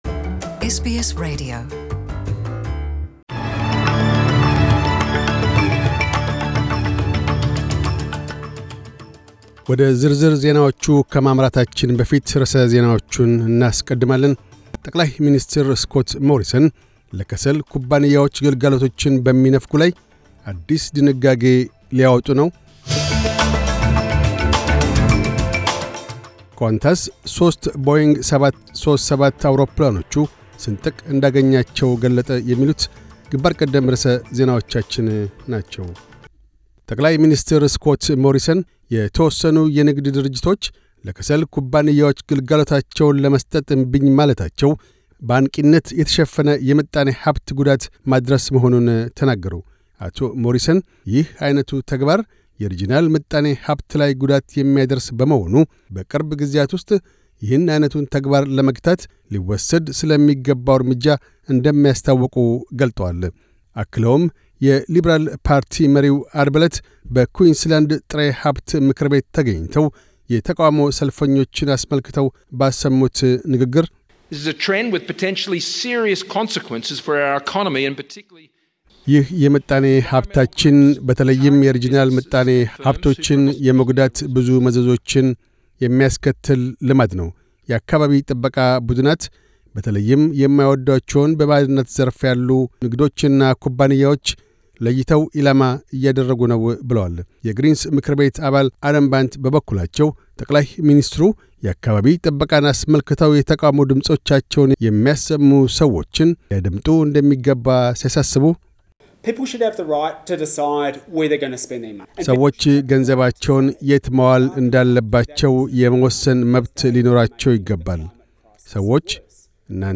News Bulletin 0111